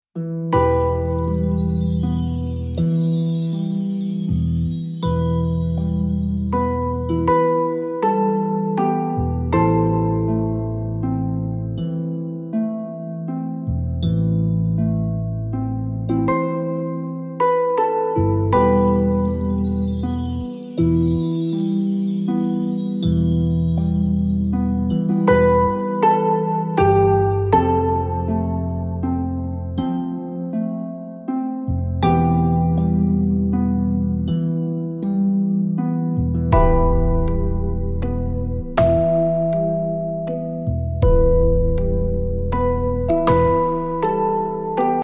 (instrumental relaxation song)